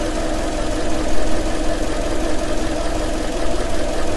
automobiles_engine.ogg